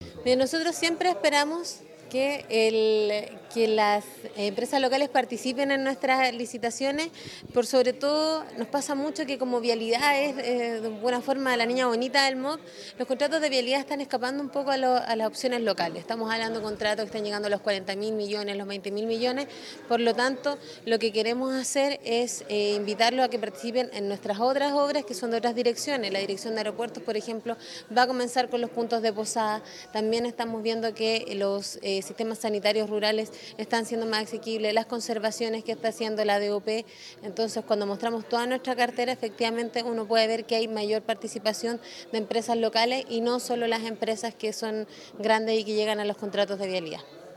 Paloma Jara / Seremi Obras Públicas Aysén